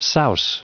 Prononciation du mot souse en anglais (fichier audio)